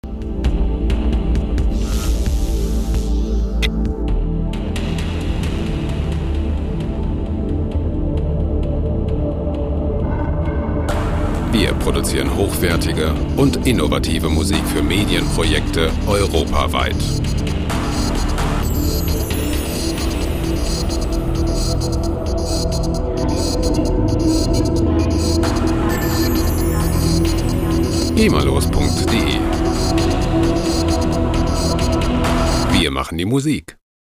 Ambient Loops gemafrei
Musikstil: Ambient
Tempo: 132 bpm